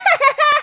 03_toad_haha.aiff